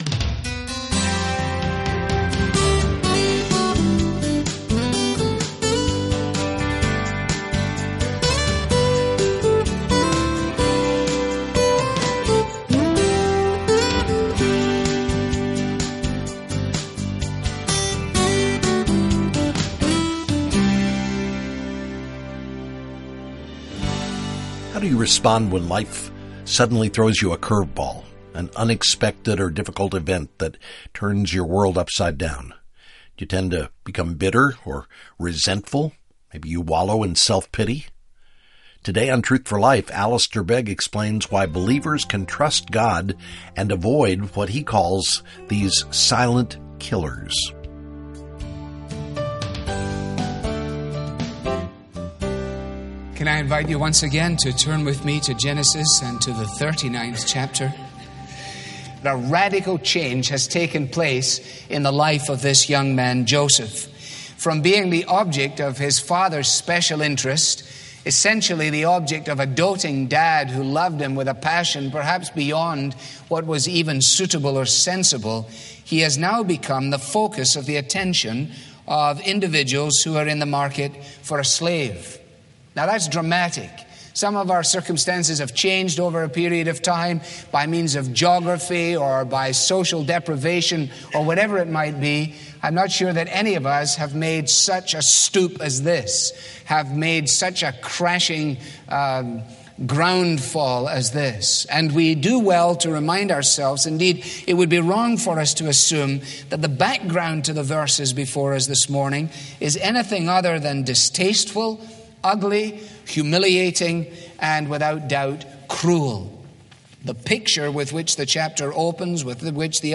• This program is part of the sermon ‘The Hand of God, Volume 1’ • Learn more about our current resource, request your copy with a donation of any amount.